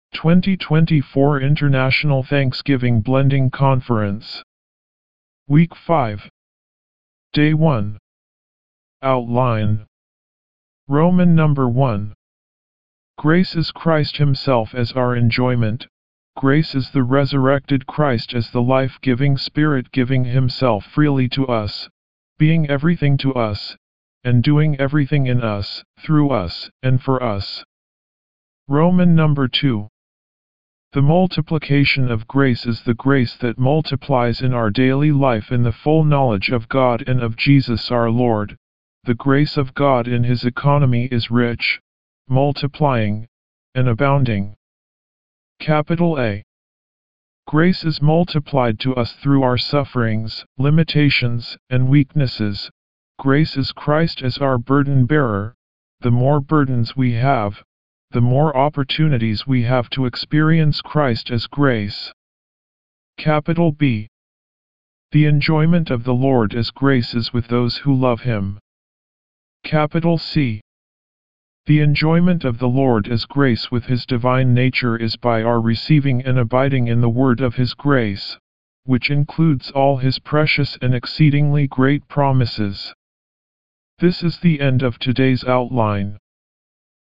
D1 English Rcite：